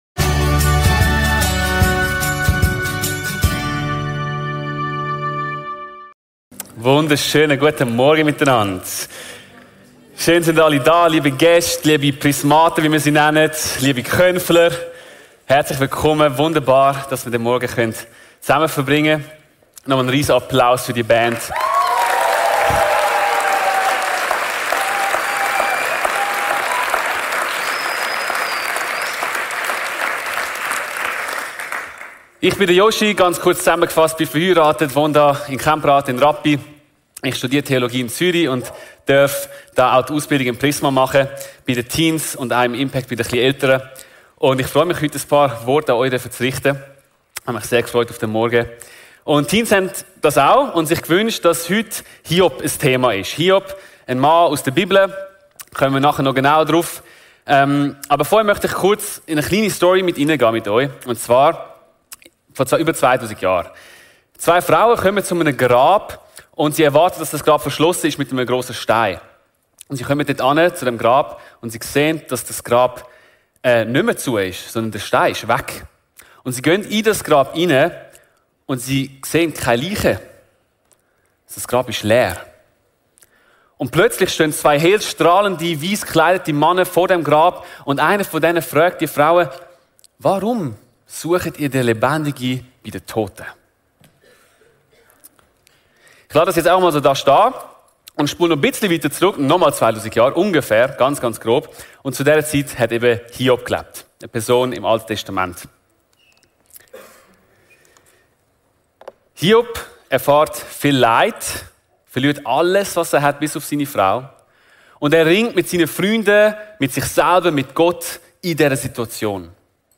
In dieser Konf-Predigt entdecken wir, wie Hoffnung mehr ist als nur positives Denken.